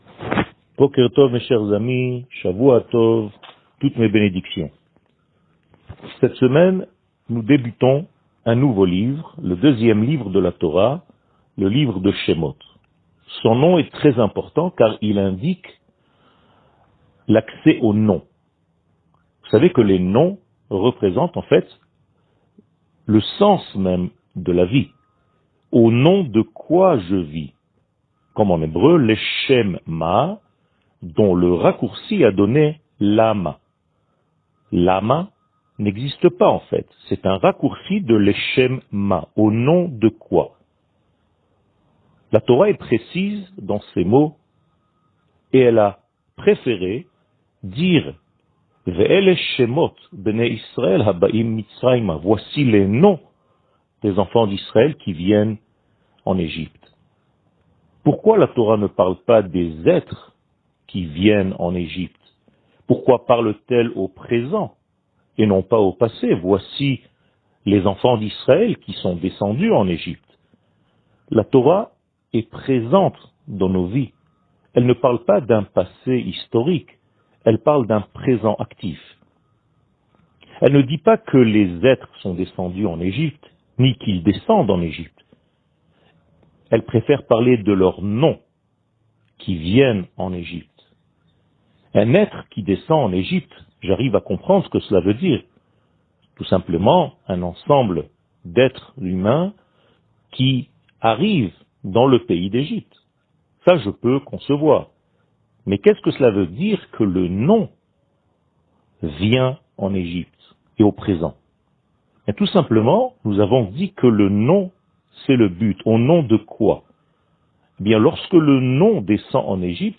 שיעור מ 05 ינואר 2021
שיעורים קצרים